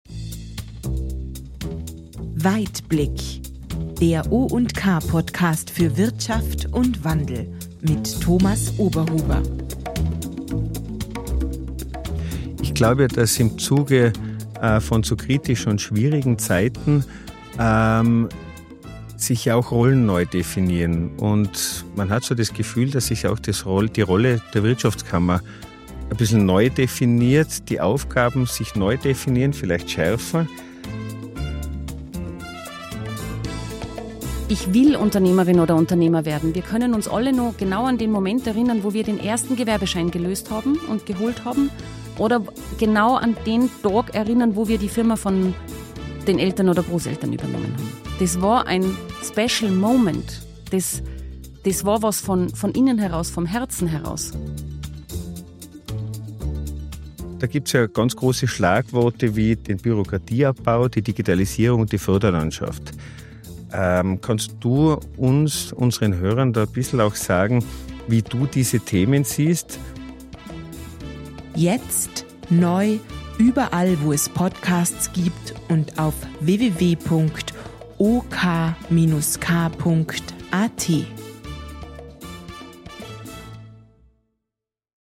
Studio in Kufstein / Tirol.